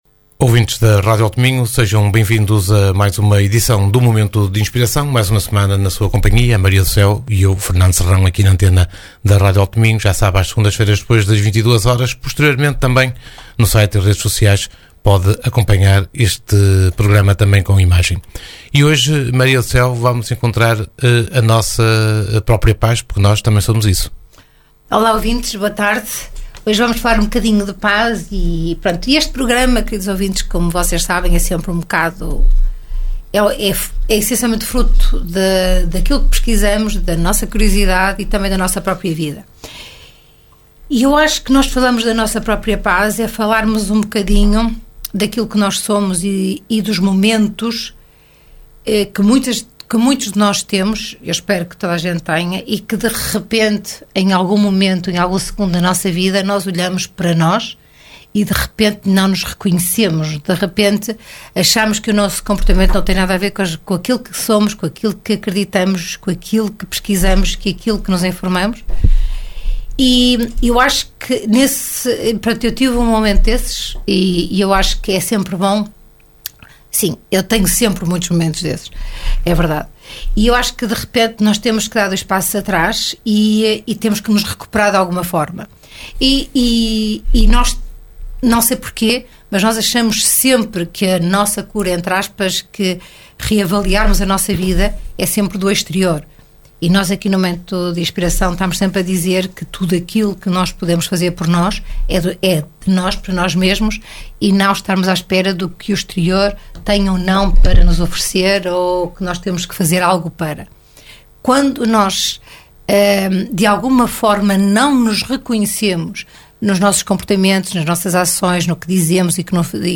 Momento de Inspiração Uma conversa a dois